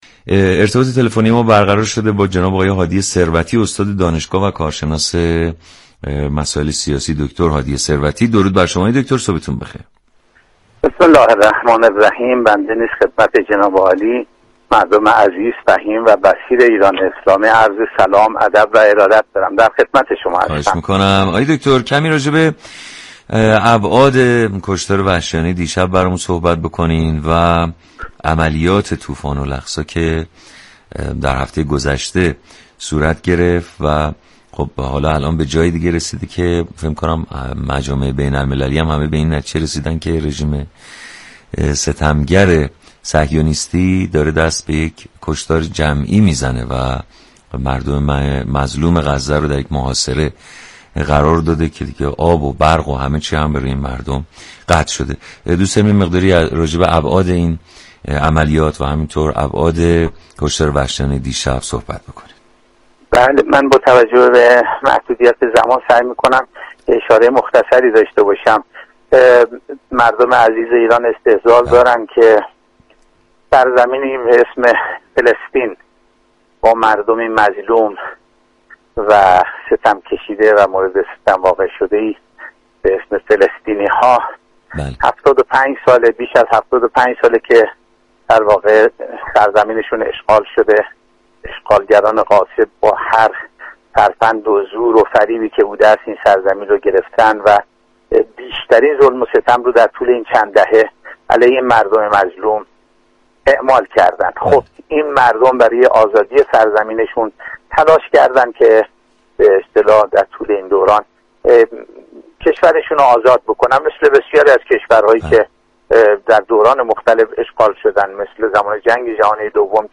برنامه زنده «صبح صبا» در حمایت از مردم غزه با موضوع وجدان راهی آنتن شد.
مخاطبان «صبح صبا» با ارسال پیامك و تماس با این برنامه با مردم فلسطین ابراز همدردی كردند و این فاجعه انسانی را محكوم كردند.